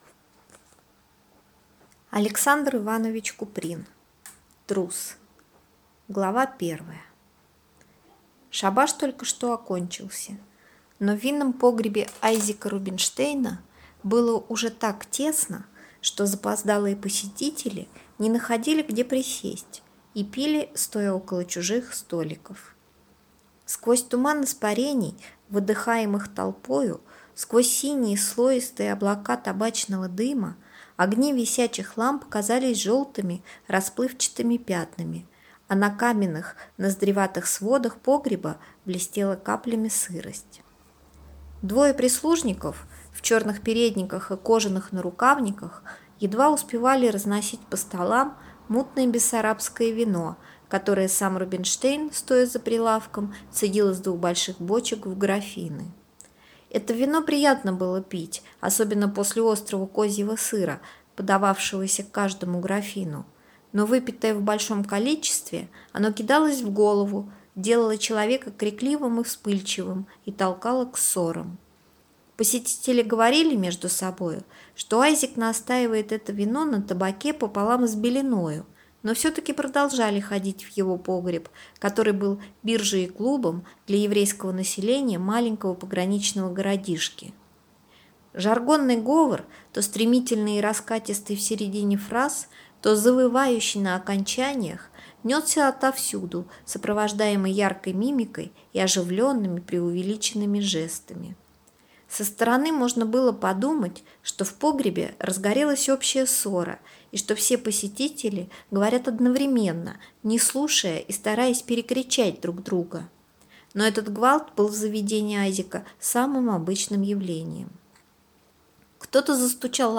Аудиокнига Трус | Библиотека аудиокниг